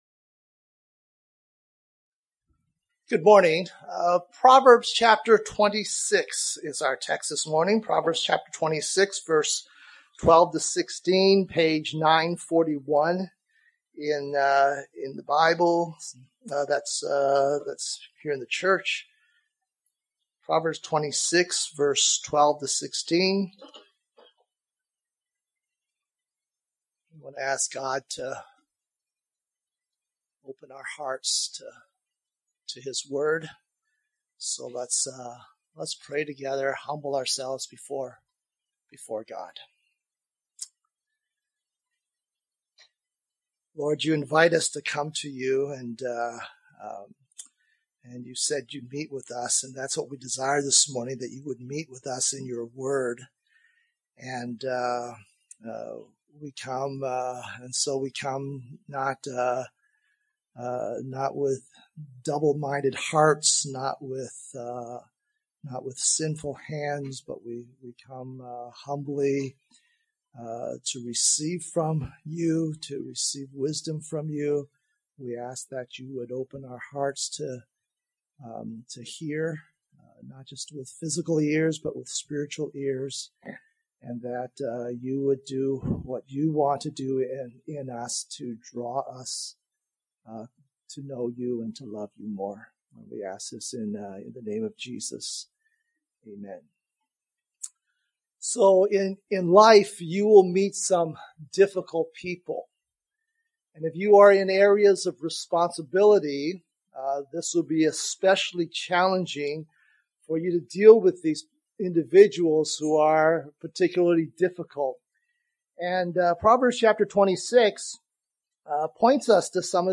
2026 Wise in His Own Eyes Preacher